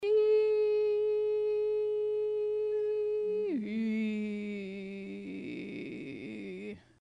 Falsetto Register - Laryngopedia
Falsetto register is applied especially to men as the high “feminine” quality sound available to most above the chest register.
Susatined-falsetto-to-chest_01.mp3